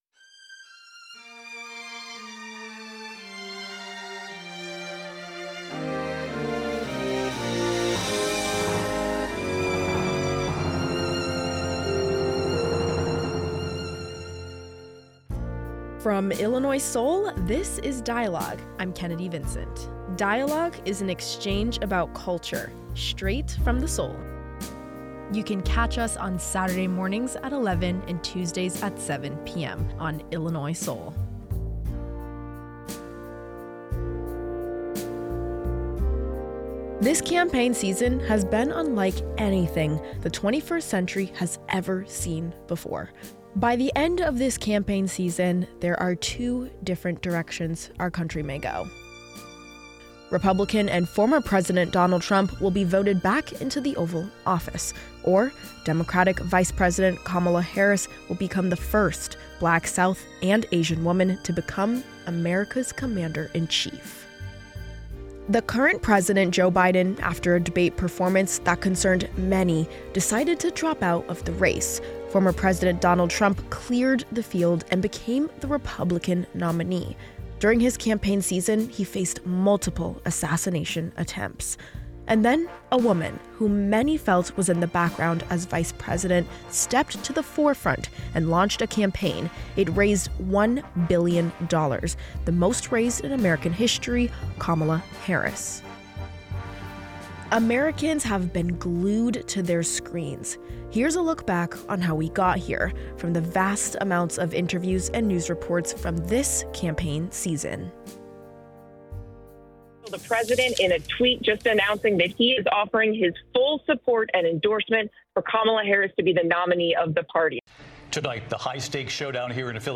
Carol Mosley Braun U.S Senator representing Illinois, 1993-1999